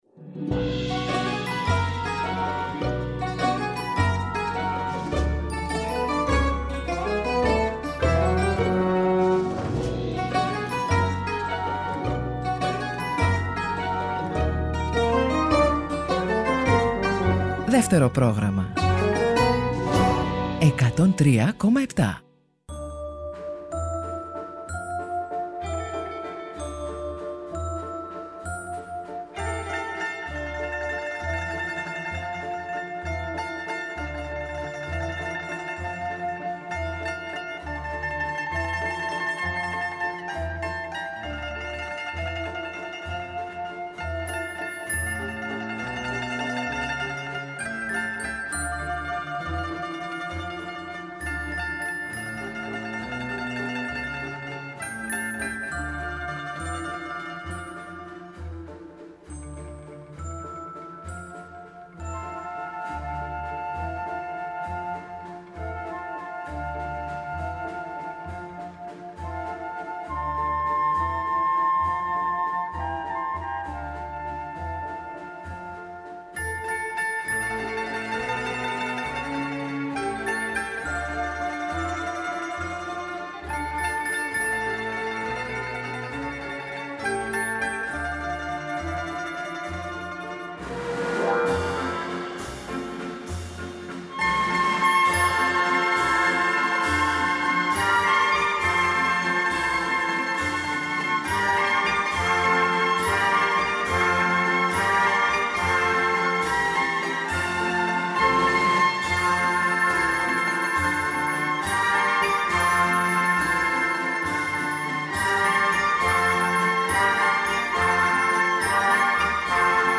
Φωνές ηθοποιών που ηχογράφησαν για το ραδιόφωνο σε αποσπάσματα από θεατρικά έργα και τραγούδια από παραστάσεις και ραδιοφωνικές επιθεωρήσεις.